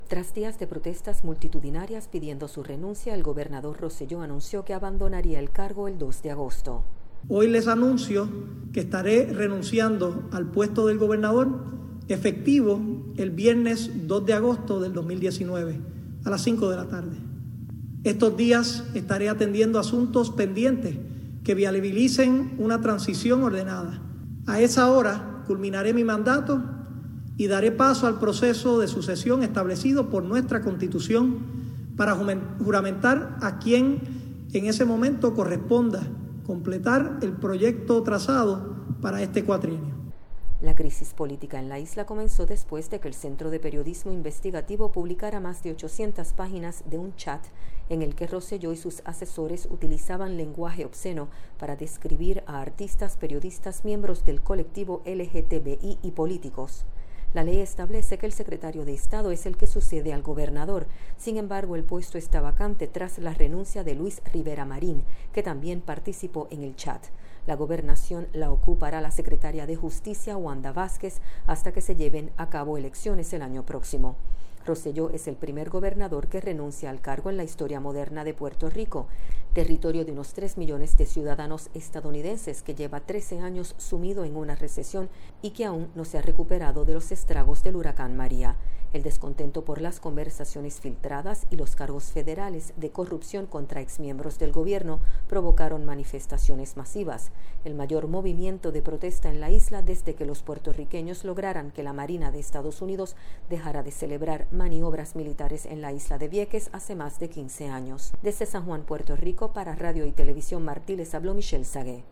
informa desde San Juan